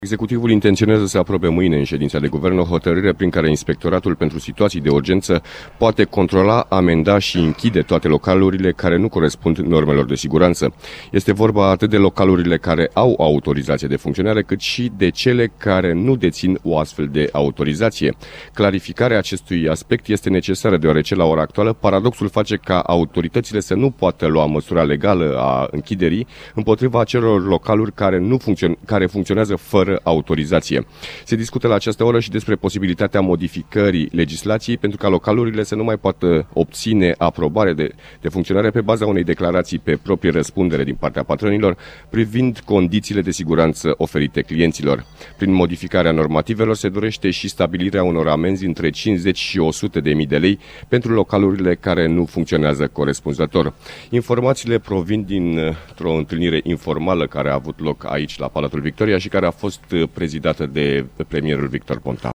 Anunțul a fost făcut de premierul Victor Ponta la reuniunea grupului de lucru de la Palatul Victoria.